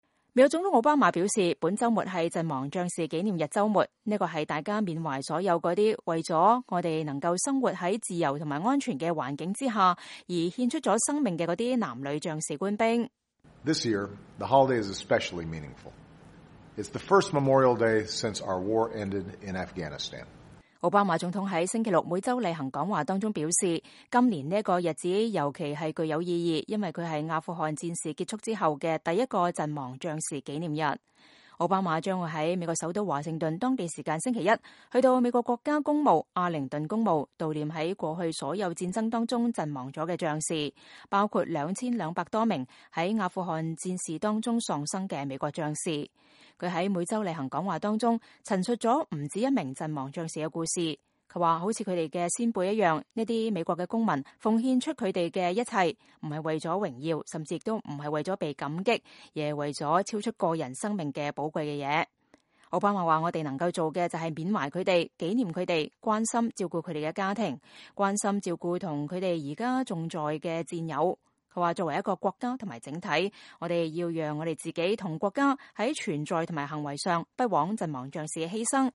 奧巴馬總統發表每週例行講話2015年5月